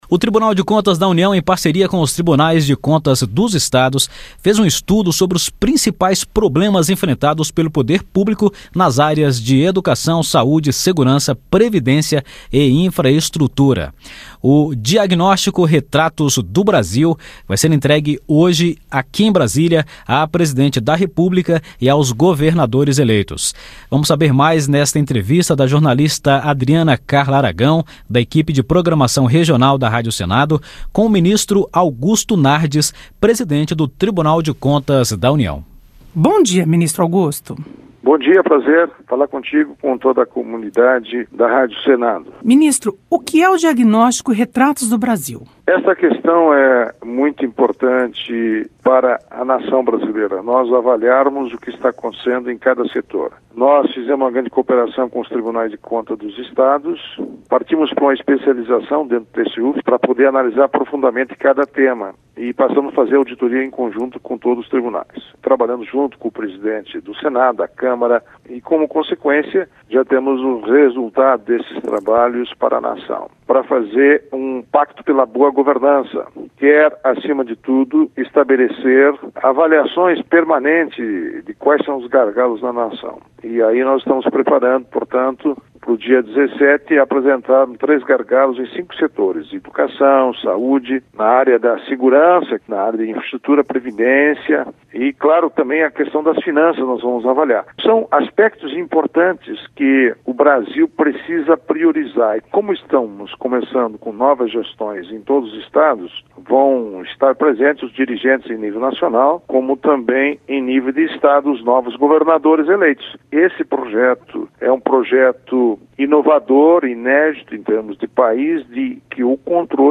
Entrevista com ministro Augusto Nardes, presidente do Tribunal de Contas da União.